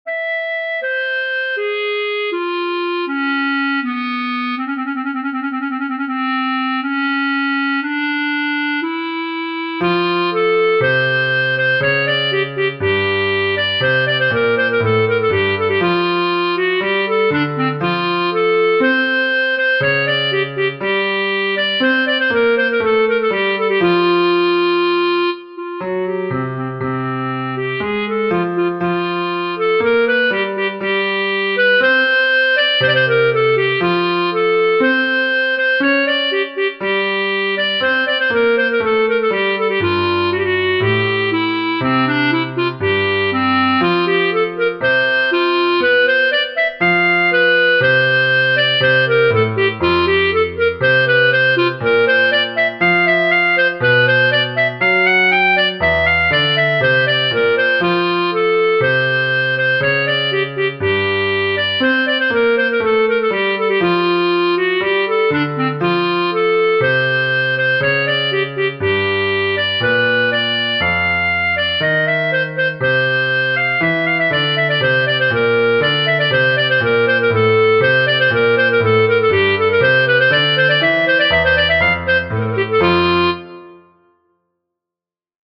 Mozartini Clarinet & Piano (unfinished piano)